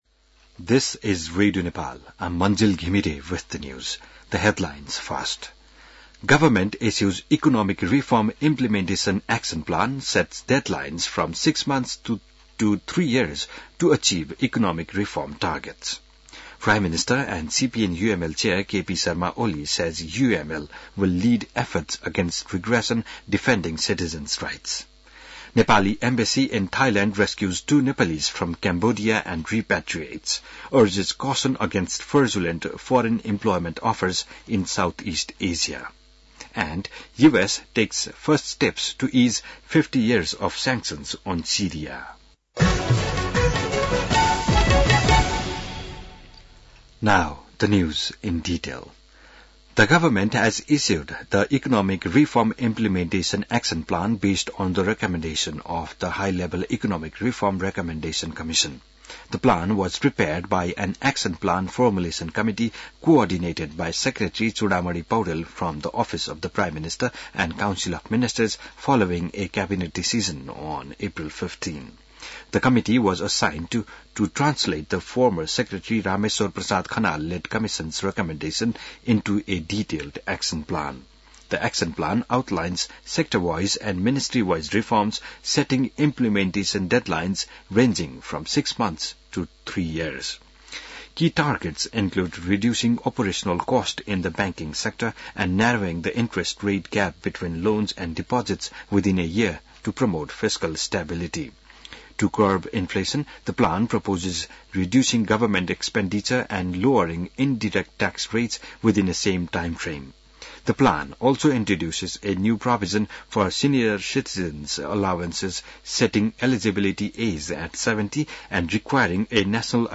बिहान ८ बजेको अङ्ग्रेजी समाचार : १० जेठ , २०८२